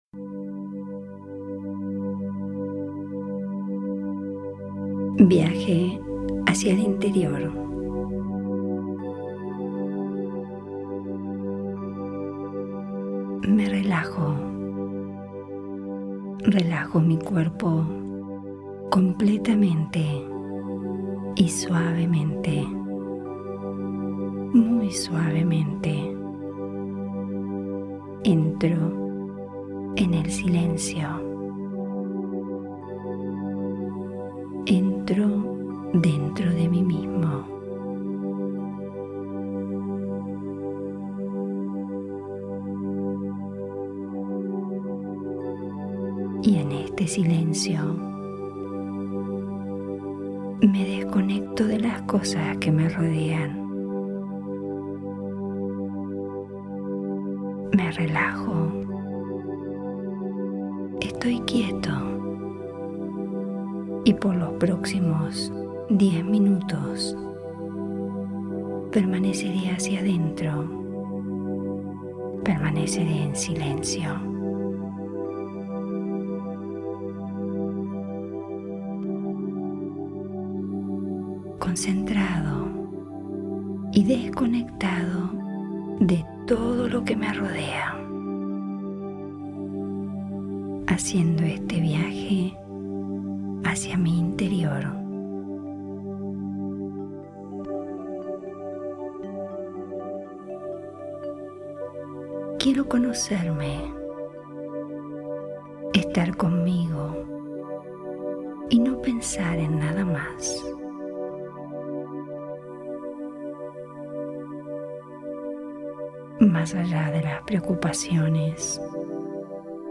meditaciones-guiadas